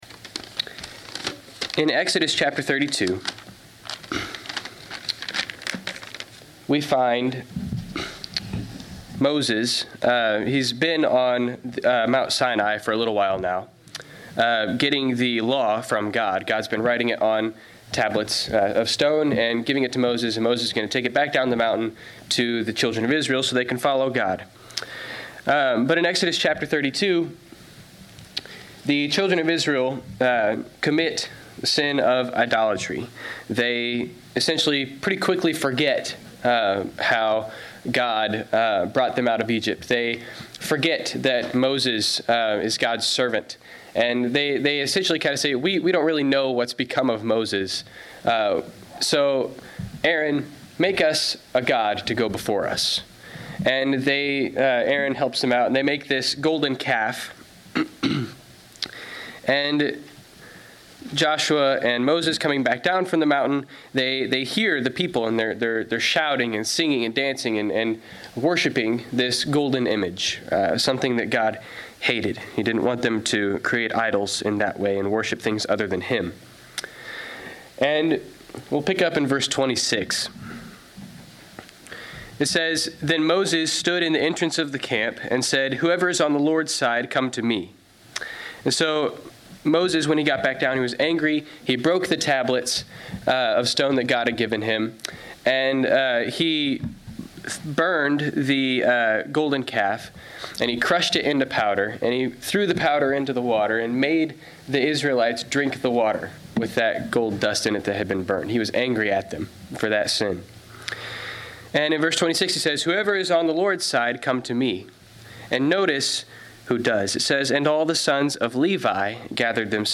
Passage: Malachi 2 Service Type: Sunday 11:00 AM Topics